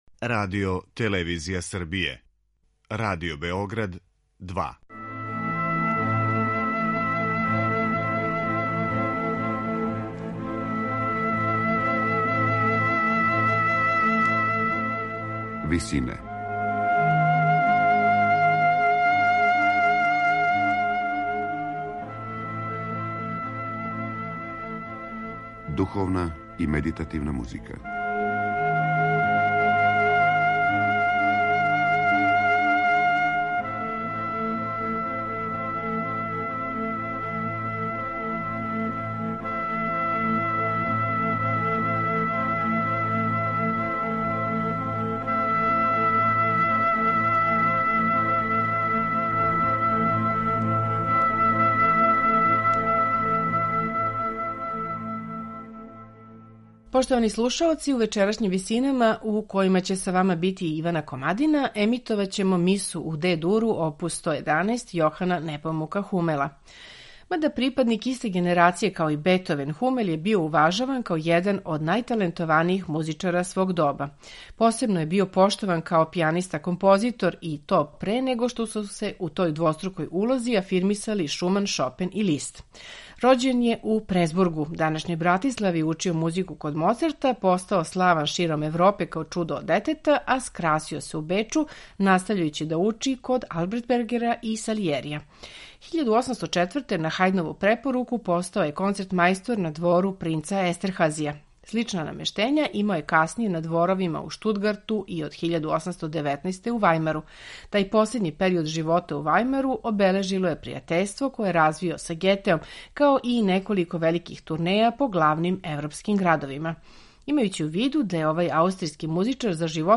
Слушаћемо је у интерпретацији ансамбла Collegium musicum 90, под управом Ричарда Хикокса.